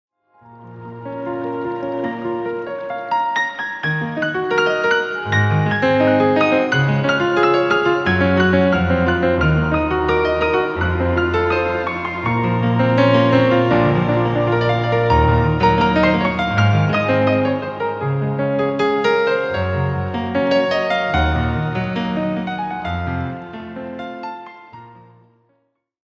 álbum instrumental